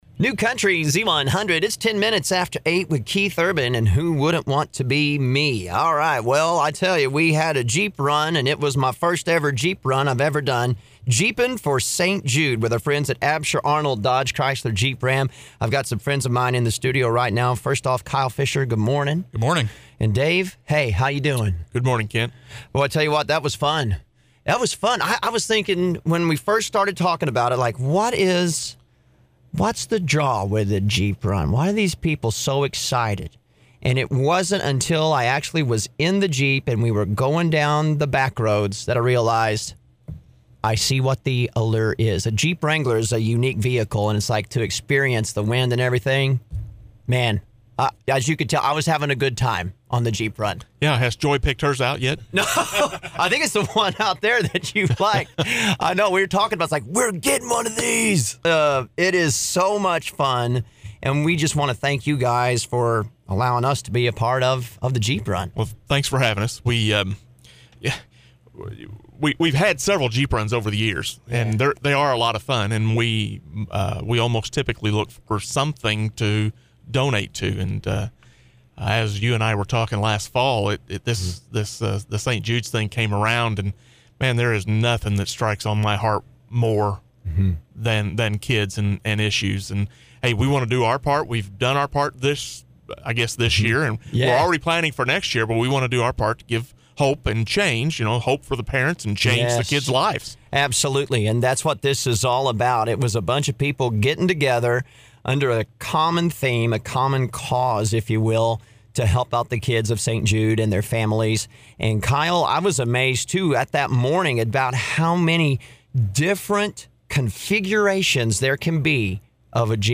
Morning Show!